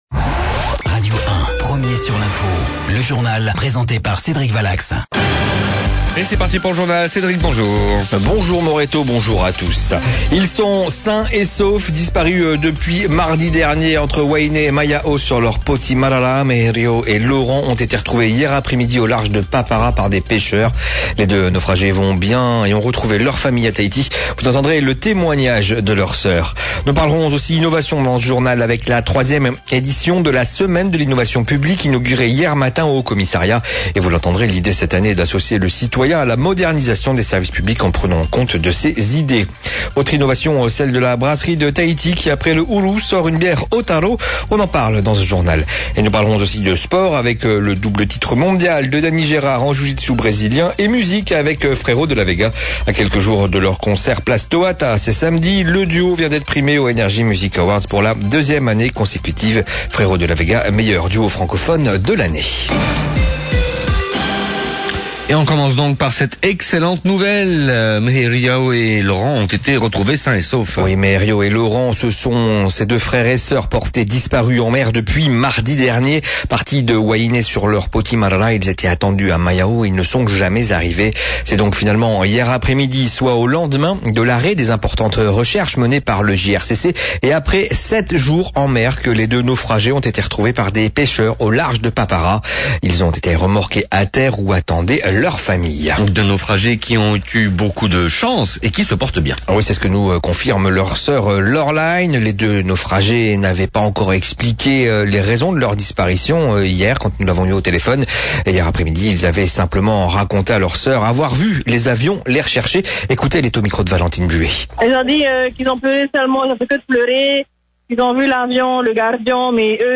Journal de 07:30 le 15/11/16